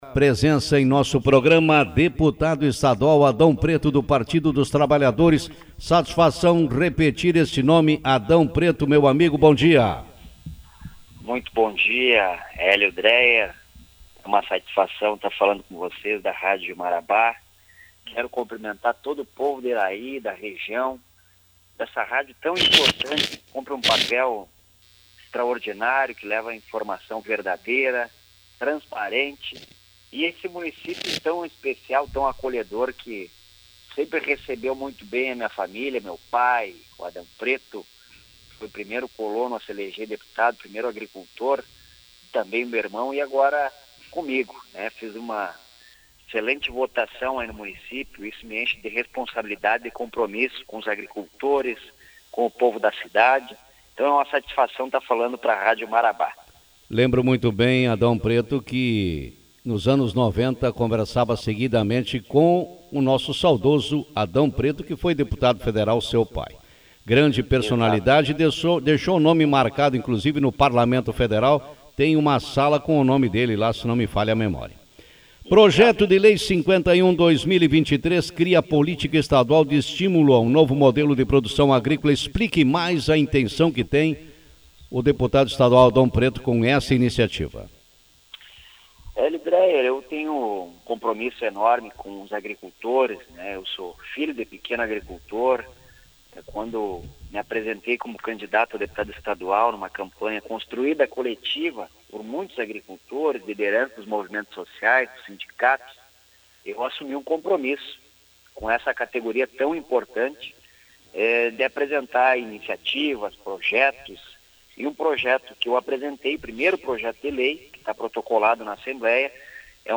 Deputado estadual Adão Pretto fala sobre atuação no Legislativo gaúcho Autor: Rádio Marabá 28/02/2023 Manchete O deputado estadual Adão Pretto, PT, participou do programa Café com Notícias e destacou as principais pautas, do momento, no seu primeiro mandato no Legislativo estadual. Acompanhe a entrevista